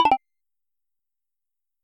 SFX_UI_Resume.mp3